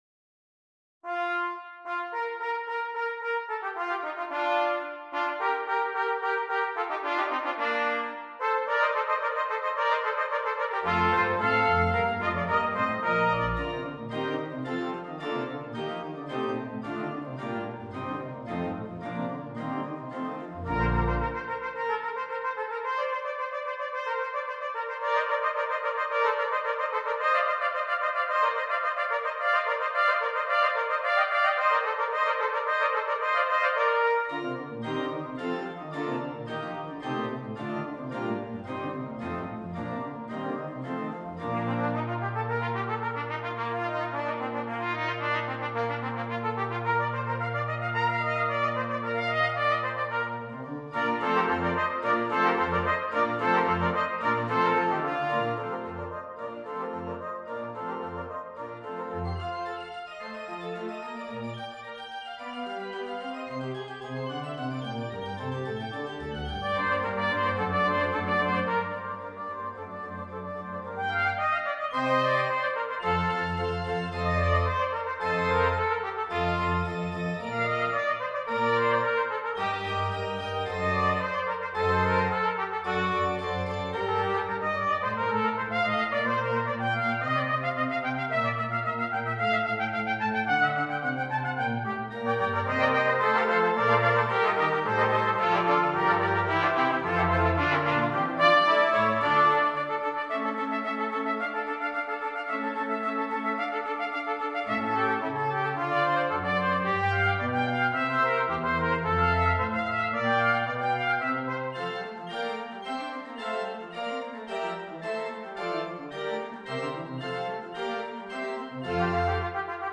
4 Trumpets and Keyboard